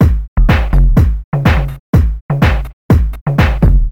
描述：高端电子管的声音，清脆的grooveloop瞬态控制（门控）。
Tag: 124 bpm Dance Loops Groove Loops 673.27 KB wav Key : Unknown